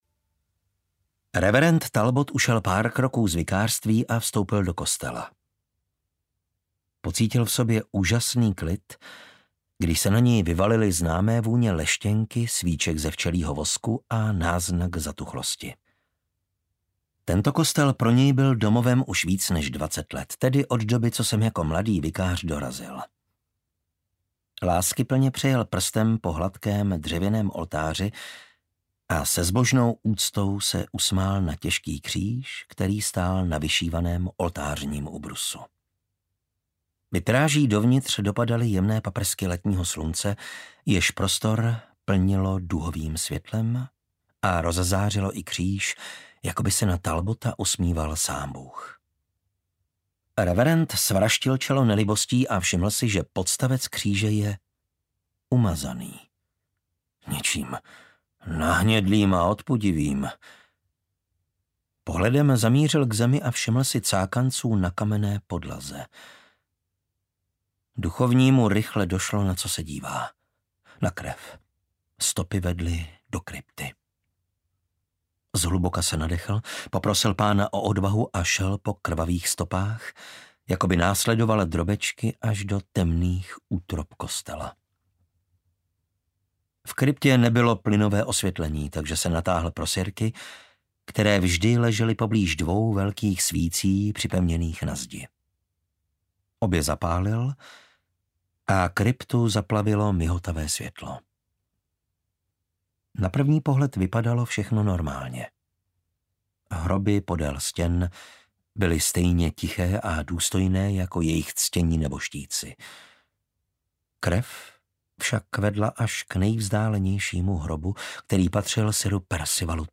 Vražda v kryptě audiokniha
Ukázka z knihy
vrazda-v-krypte-audiokniha